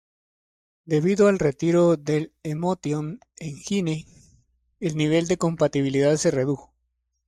Pronounced as (IPA) /reˈtiɾo/